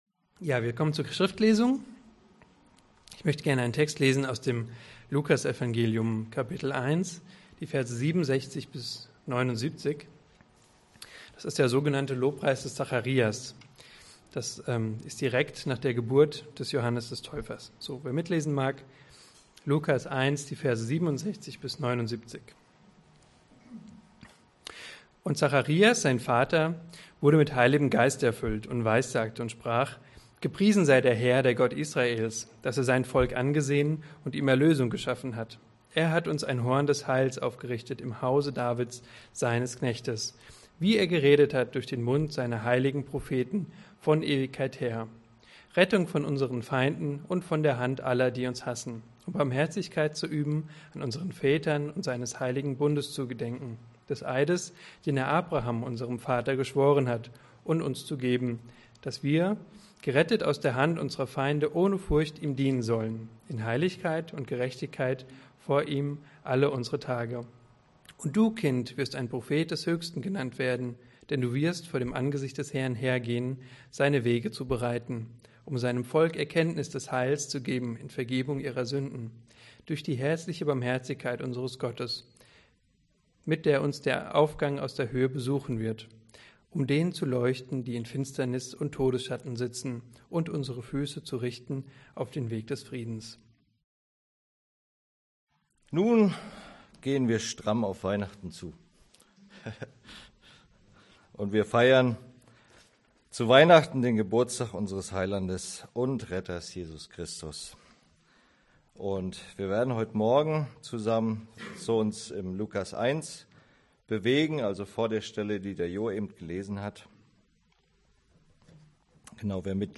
Predigt Die Herrschaft Jesu Veröffentlicht am 30.